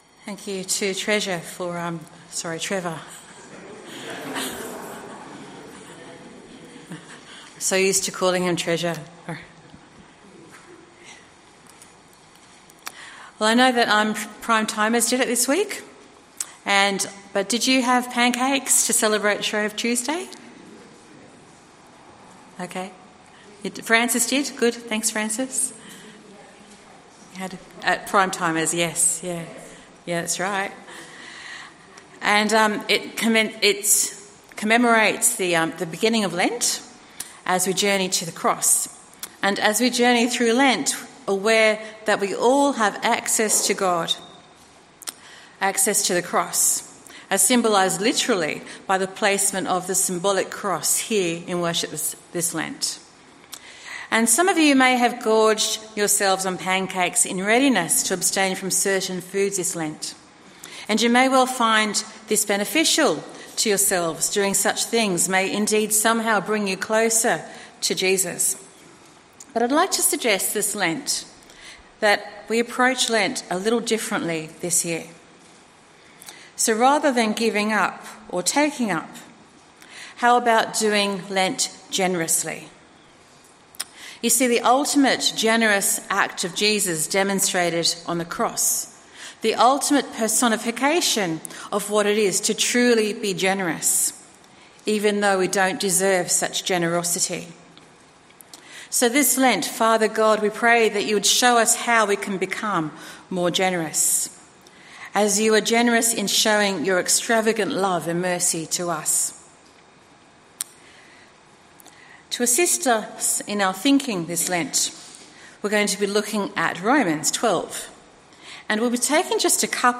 Sermon and Bible Reading from the 10AM meeting at Newcastle Worship & Commnunity Centre of The Salvation Army. The Bible reading was taken from Romans 12:1-2.